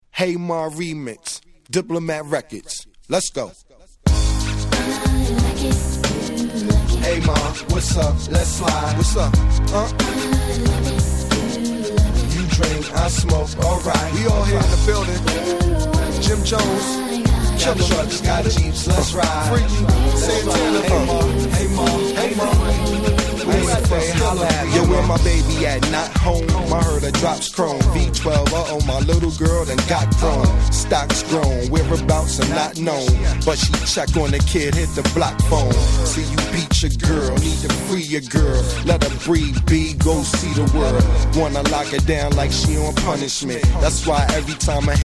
02' 大Hit Hip Hop !!